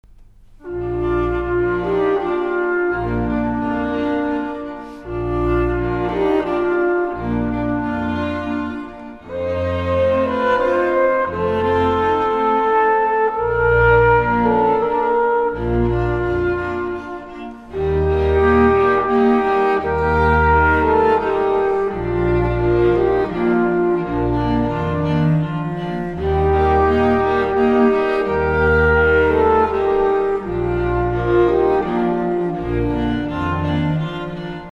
Trompete
Violine
Viola
Violoncello
Contrabass
in der Auferstehungskirche Neu-Rum